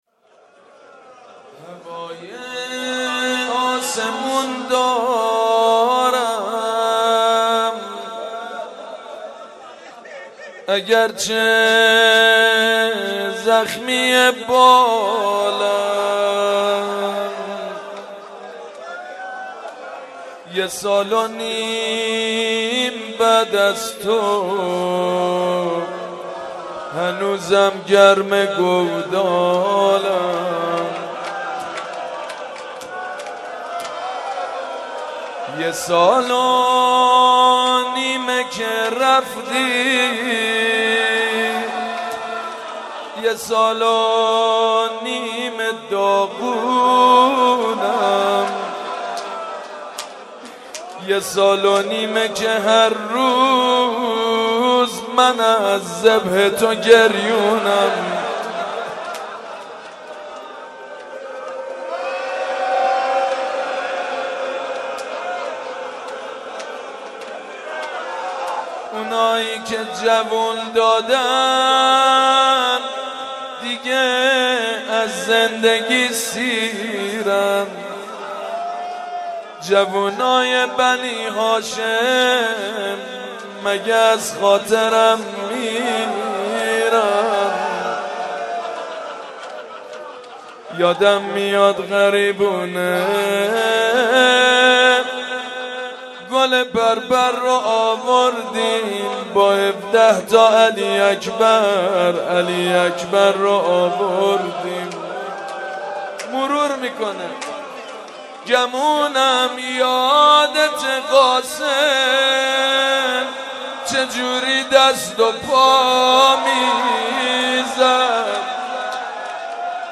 مداح : سیدمجید بنی‌فاطمه قالب : روضه